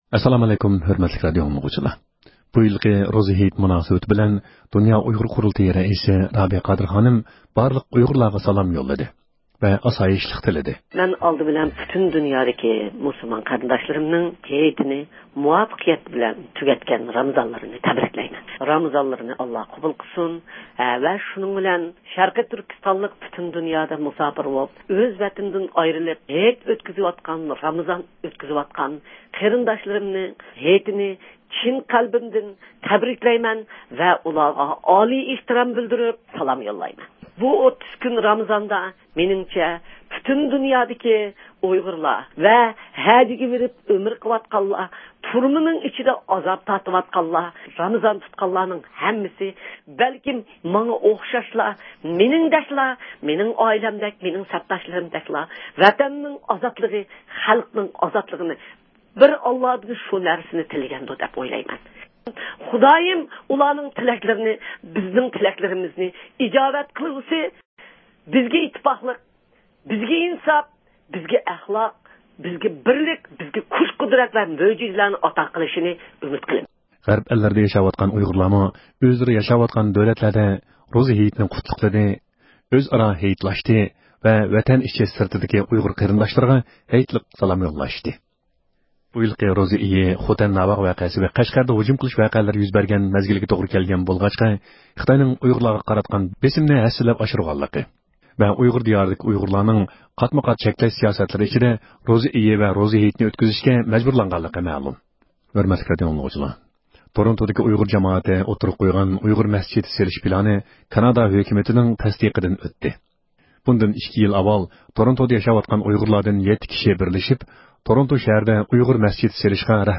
ھەپتىلىك خەۋەرلەر (27-ئاۋغۇستتىن 2-سېنتەبىرگىچە) – ئۇيغۇر مىللى ھەركىتى